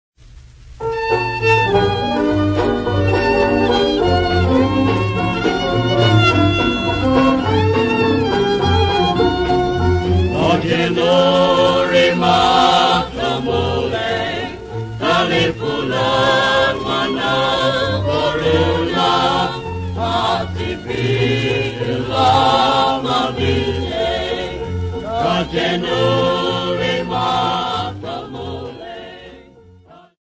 Popular music--Africa
Dance music
Dance music--Caribbean Area
sound recording-musical
Quintette song accompanied by a jazz band